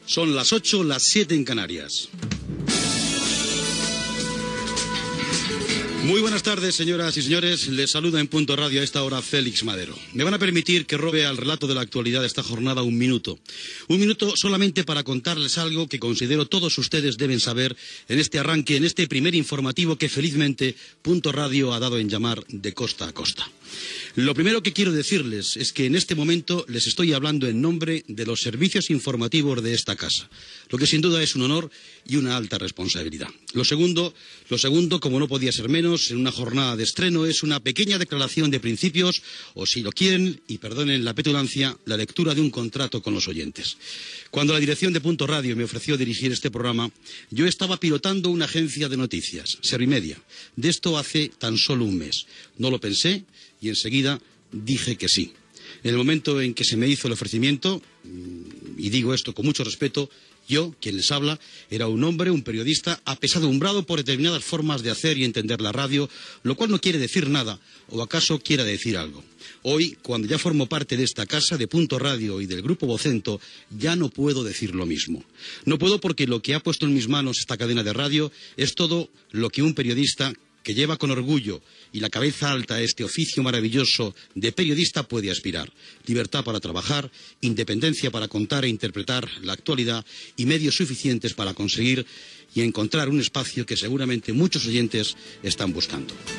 Hora, salutació en l'inici del primer programa i declaració de la intenció de principis
Informatiu